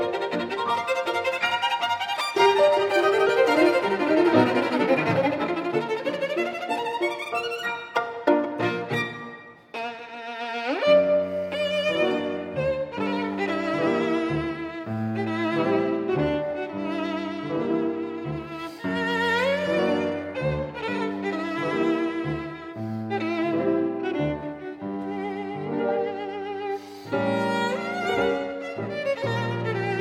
0 => "Musique de chambre"